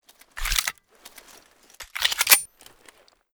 92fs_reload_empty.ogg